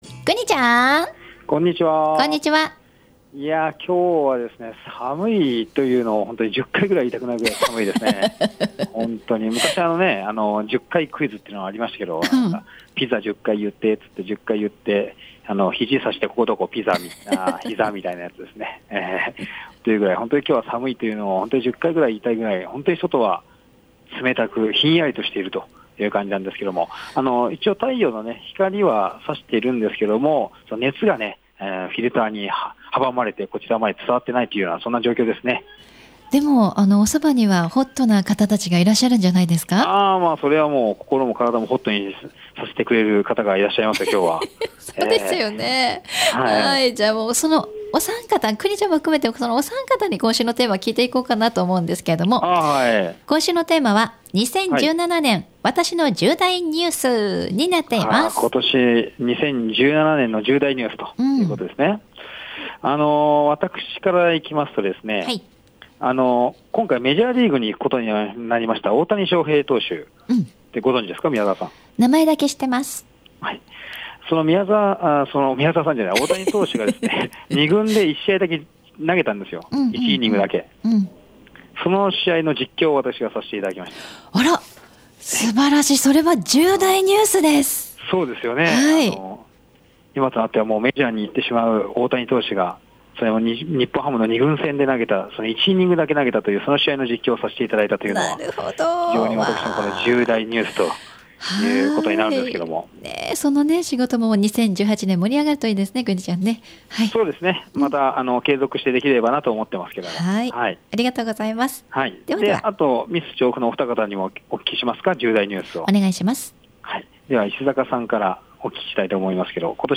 街角レポート
中継でお邪魔した際にも、若い方から年配の方まで 幅広い方がランチにコーヒーに舌鼓を打っていました。